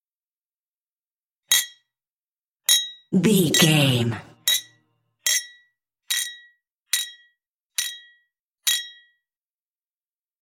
Glass clink
Sound Effects
foley